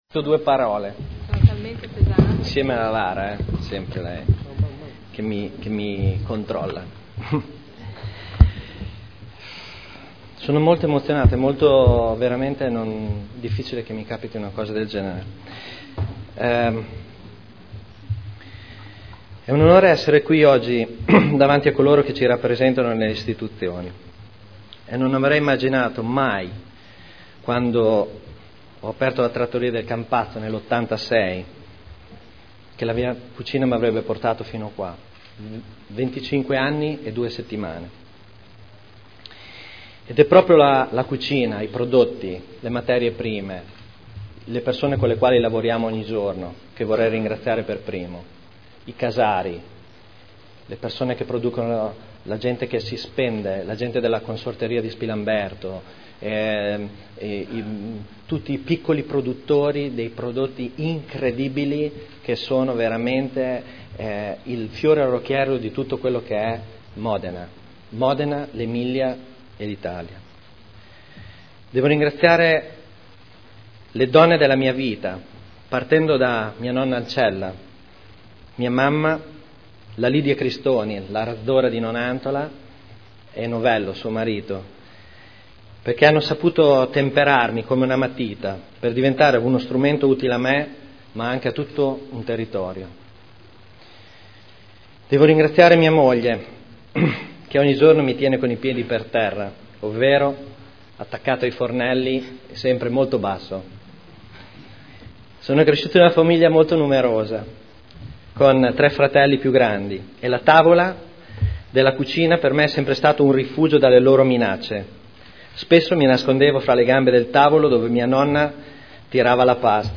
Seduta del 04/04/2011 interviene alla Cerimonia di premiazione a lui dedicata.